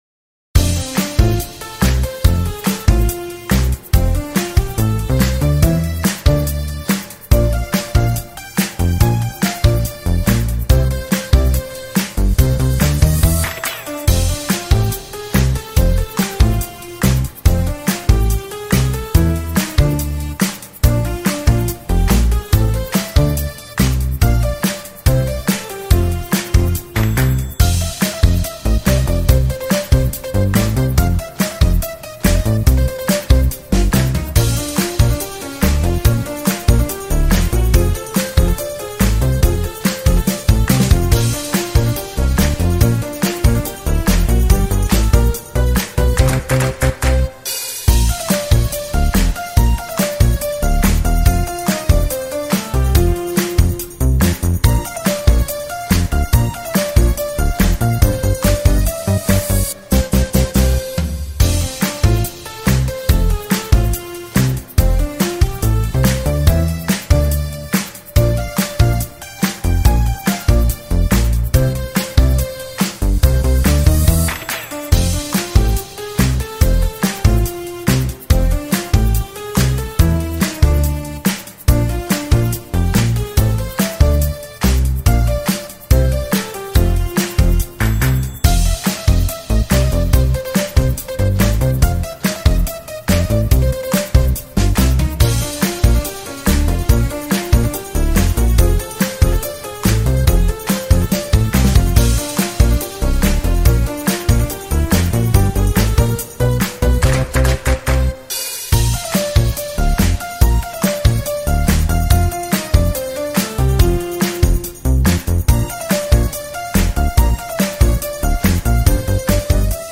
ost